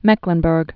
(mĕklən-bûrg, -brk)